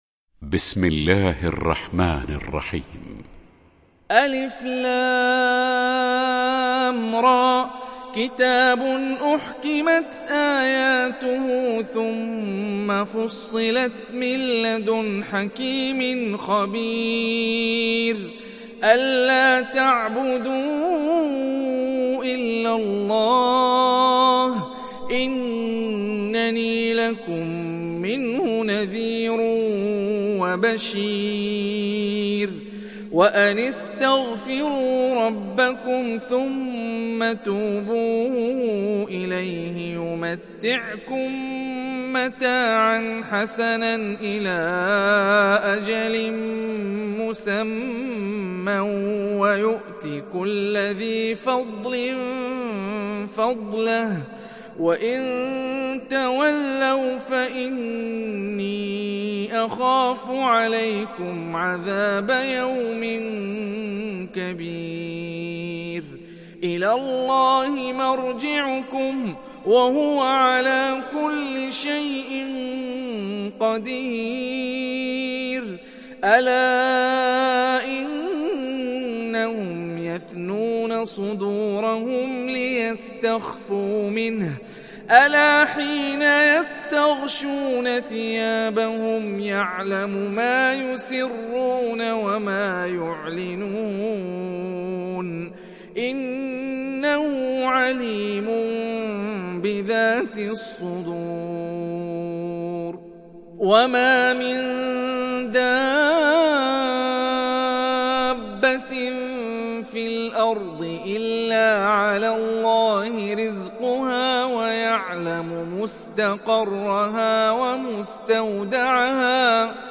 تحميل سورة هود mp3 بصوت هاني الرفاعي برواية حفص عن عاصم, تحميل استماع القرآن الكريم على الجوال mp3 كاملا بروابط مباشرة وسريعة